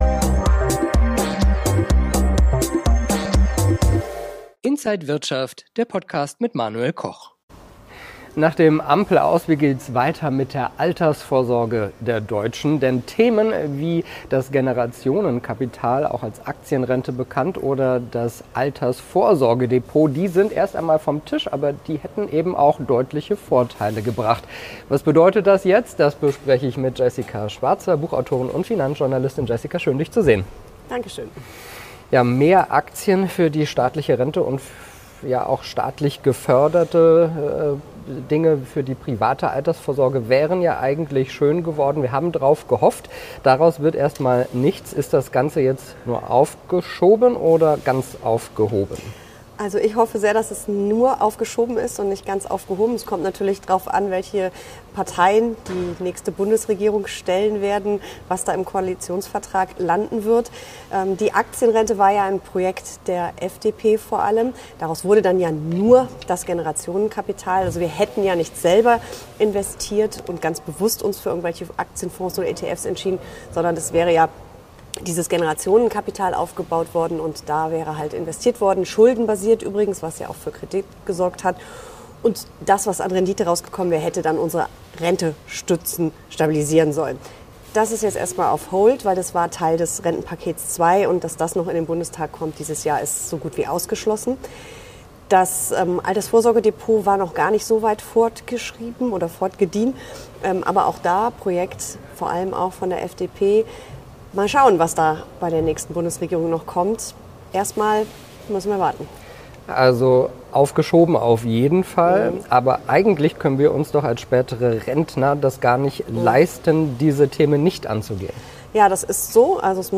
verrät die Buchautorin und Finanzjournalistin im Interview von